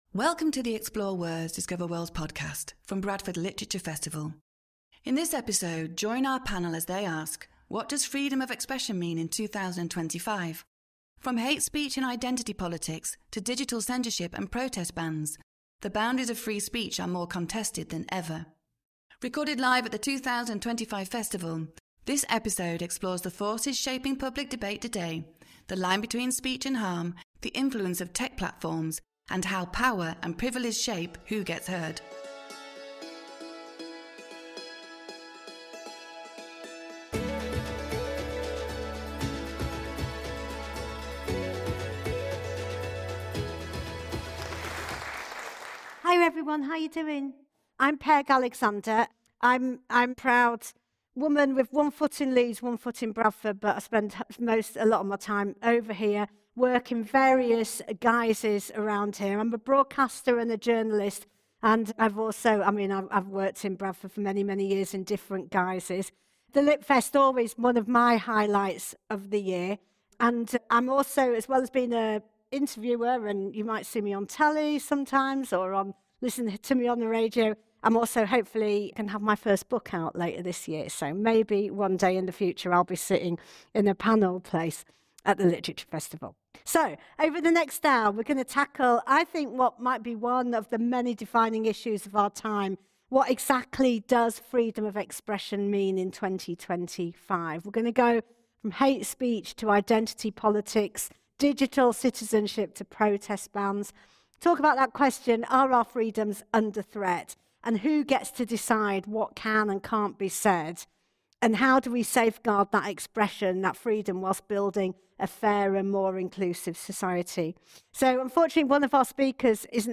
This timely event explores the forces shaping public debate today: the line between speech and harm, the influence of tech platforms, and how power and privilege shape who gets heard. Join our panel as they ask: Are our freedoms under threat?